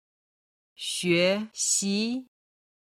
今日の振り返り！中国語発声
01-xuexi.mp3